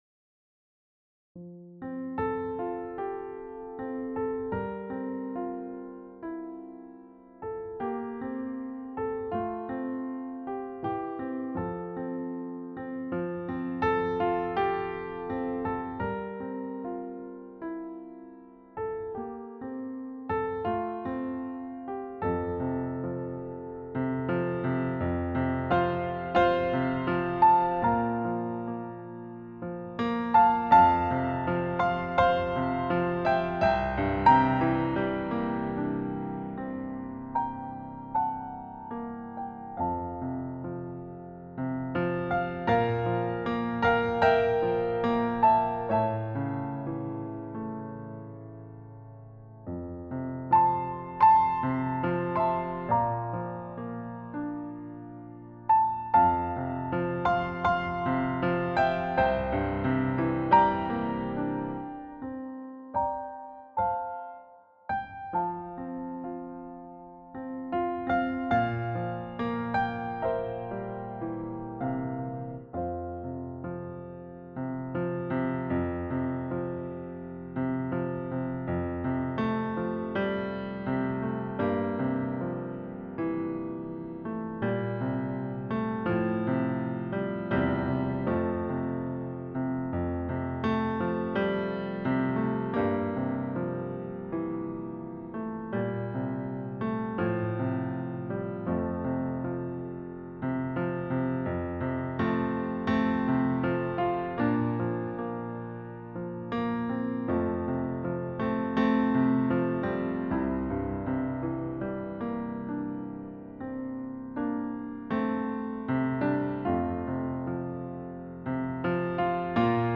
Piano Solo
Voicing/Instrumentation: Piano Solo We also have other 49 arrangements of " Be Still, My Soul ".